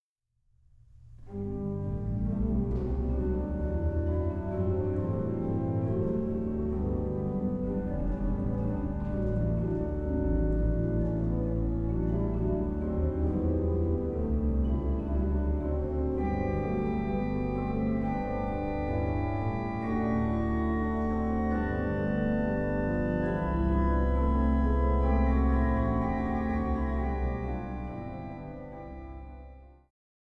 Eule-Orgel der Marienkirche zu Zwickau